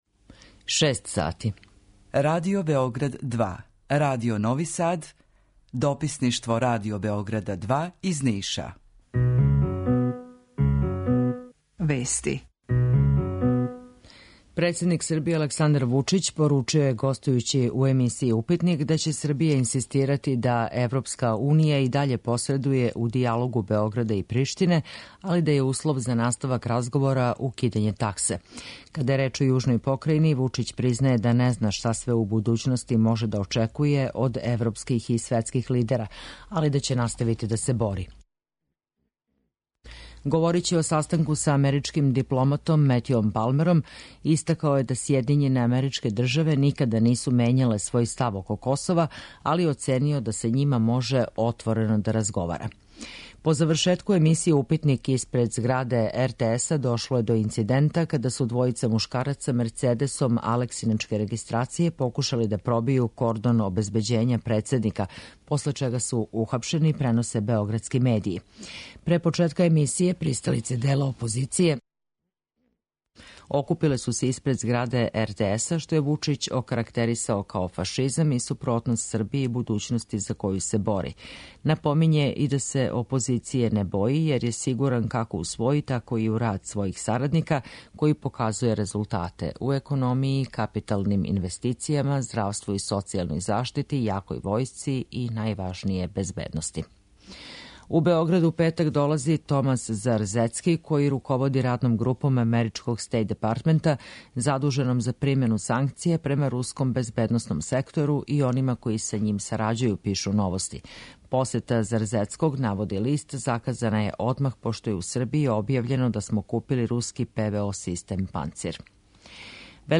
Укључење Радио Грачанице